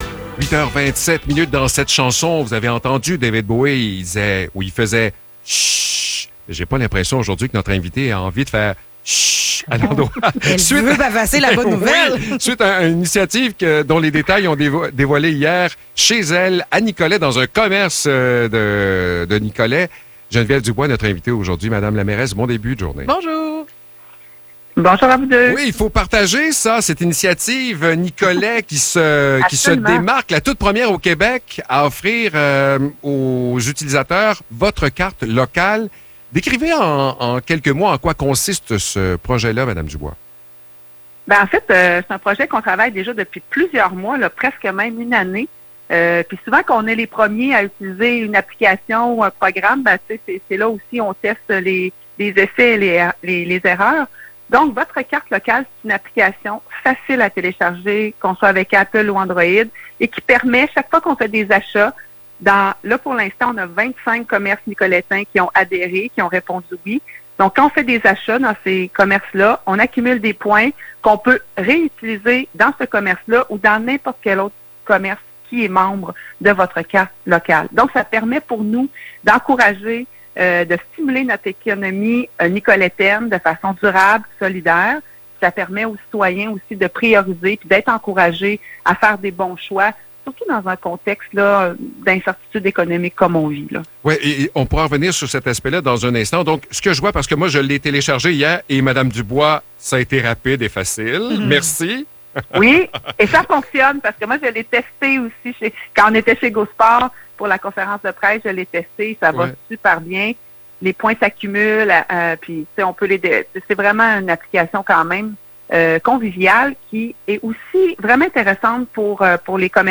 Entrevue pour l’application sur l’achat local à Nicolet
La mairesse de Nicolet Geneviève Dubois nous parle de cette nouvelle initiative faite pour augmenter l’achat local à Nicolet